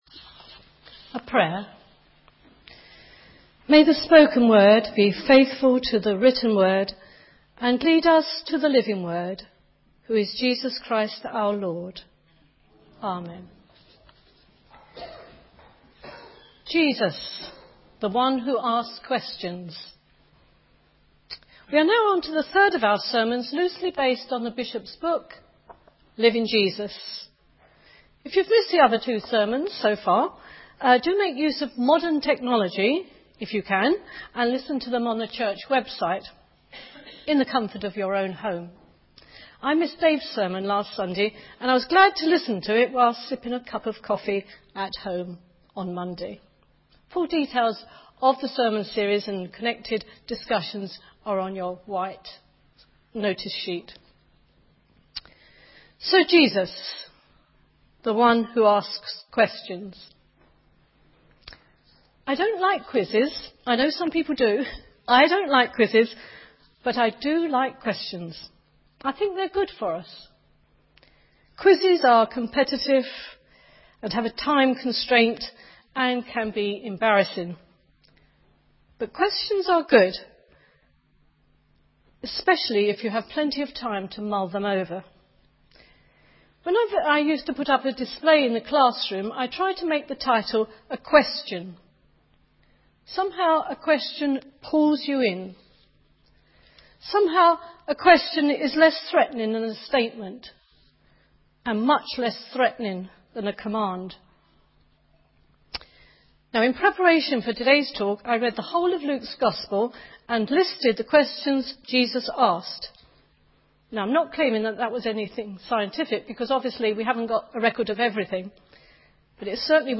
This series of sermons follows the discussions in the book to help look at aspects of Jesus’ Ministry which perhaps were not clear to us.